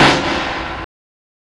SNARE 90S 5.wav